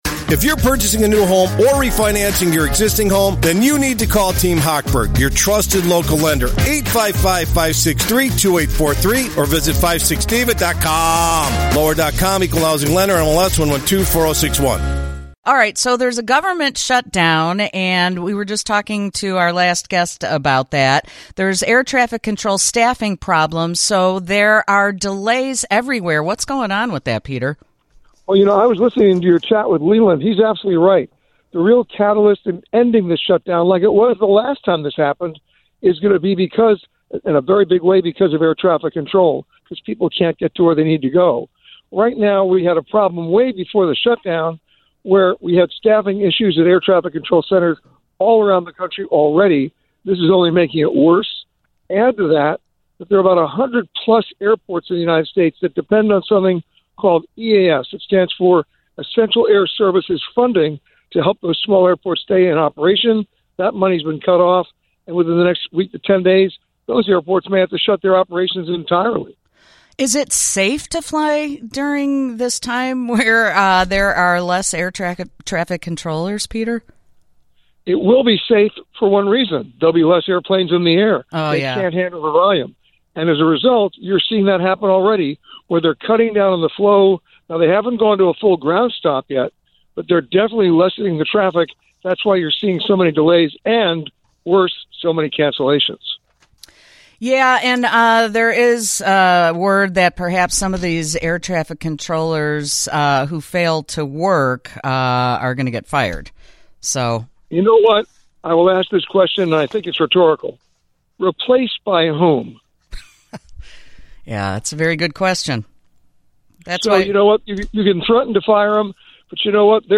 Then, as always, he answers travel questions from listeners.